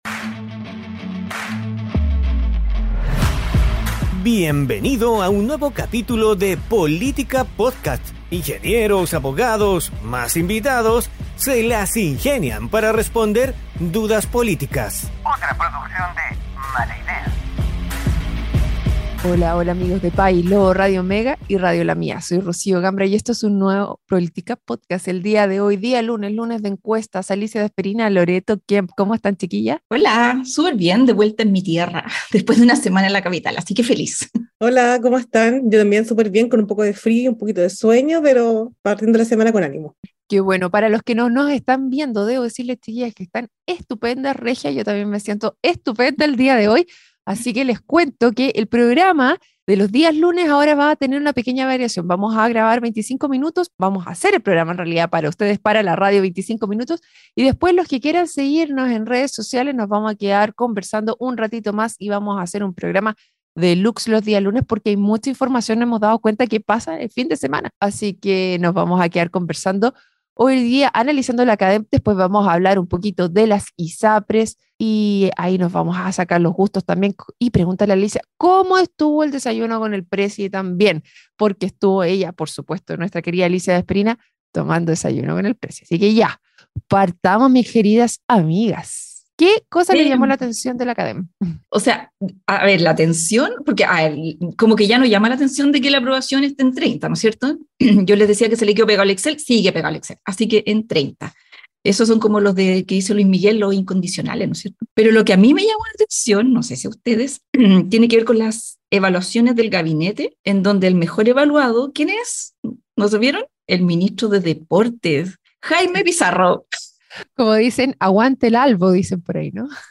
programa donde junto a panelistas estables e invitados tratan de responder dudas políticas.